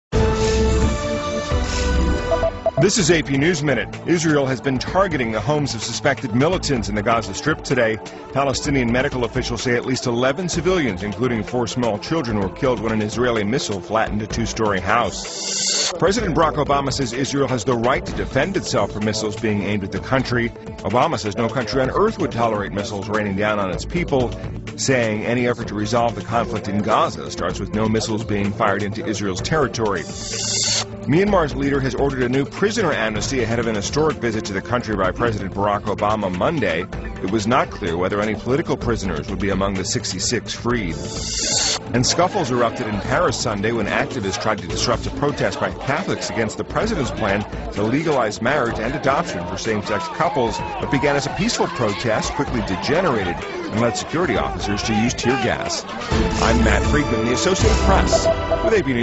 在线英语听力室美联社新闻一分钟 AP 2012-11-21的听力文件下载,美联社新闻一分钟2012,英语听力,英语新闻,英语MP3 由美联社编辑的一分钟国际电视新闻，报道每天发生的重大国际事件。电视新闻片长一分钟，一般包括五个小段，简明扼要，语言规范，便于大家快速了解世界大事。